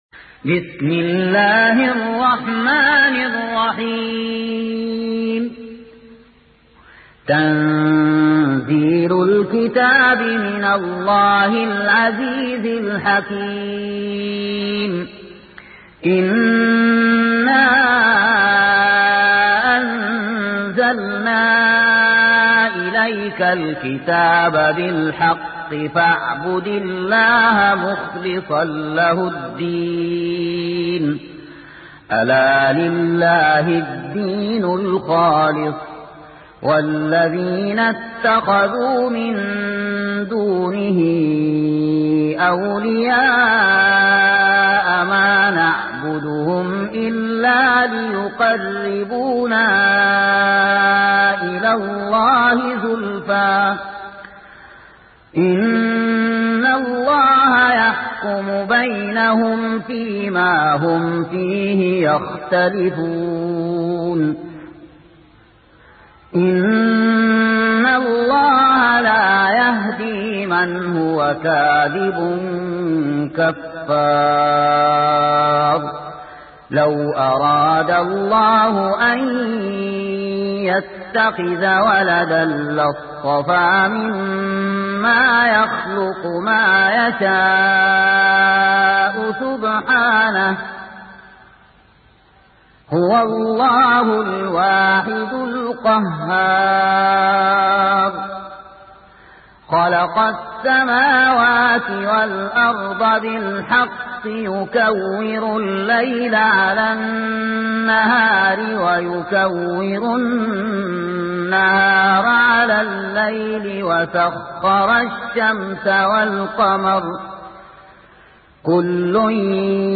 سورة الزمر مكية عدد الآيات:75 مكتوبة بخط عثماني كبير واضح من المصحف الشريف مع التفسير والتلاوة بصوت مشاهير القراء من موقع القرآن الكريم إسلام أون لاين